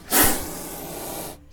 animalworld_kobra.ogg